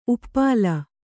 ♪ uppāla